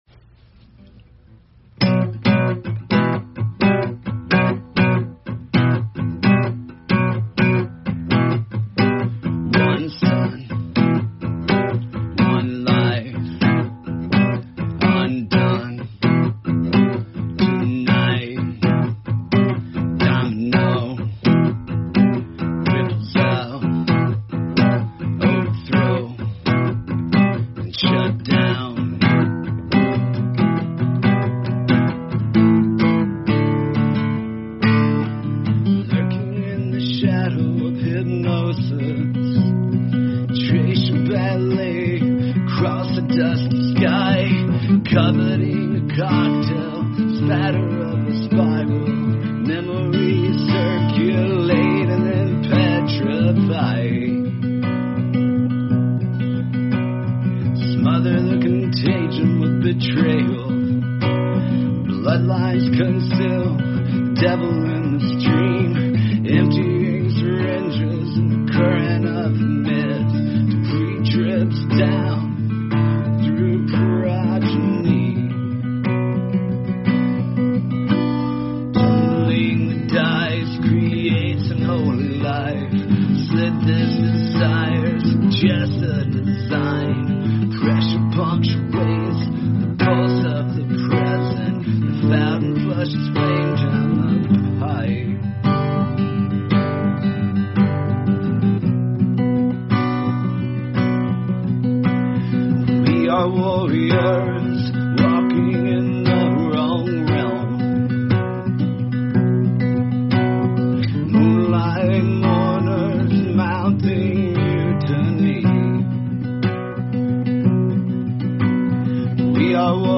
I don’t know music theory, but I think I am in the key of D. I used D, G, A, and Bm - using Bm heavily to kind of get the mood of the song.
I had to compress this a lot to get the size down to fit on here (sorry for the quality).